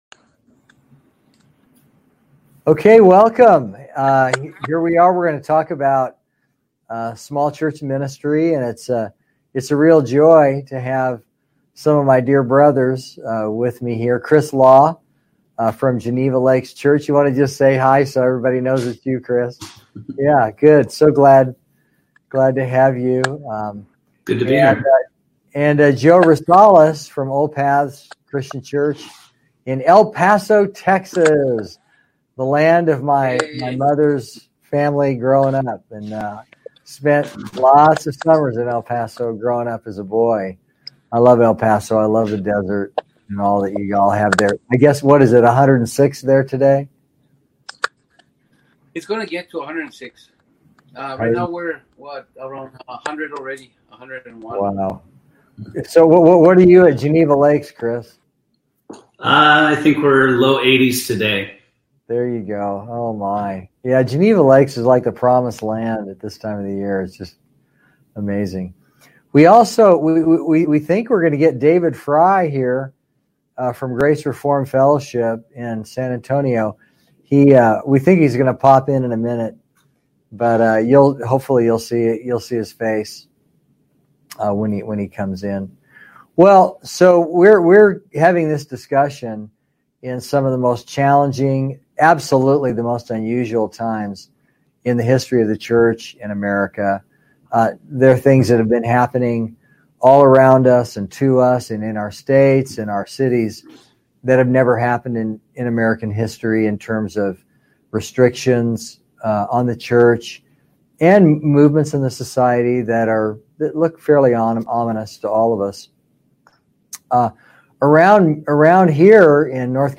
A Pastors Discussion on "Small Church Ministry"